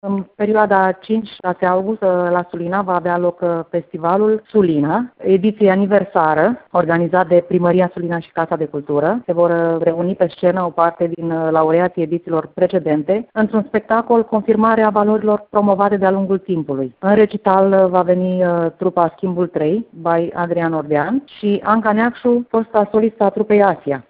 la Radio Vacanţa!